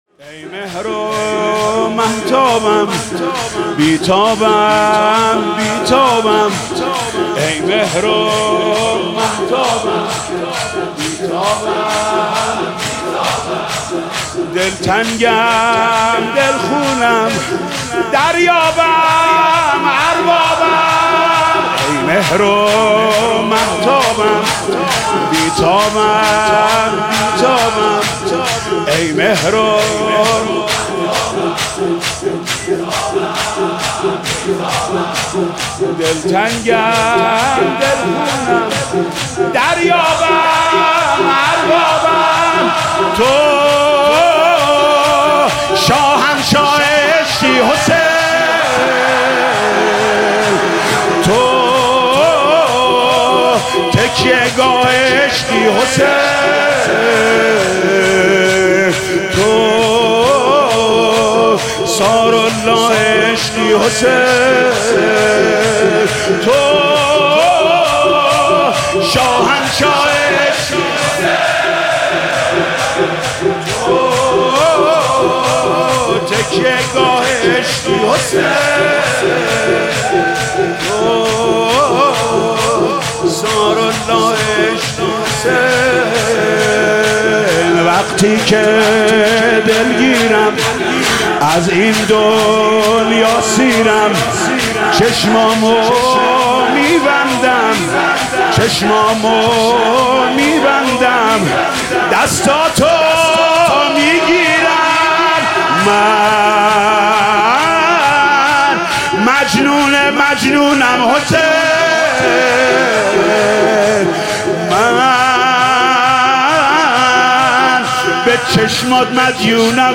مداحی شهادت امام جعفر صادق علیه السلام
(روضه)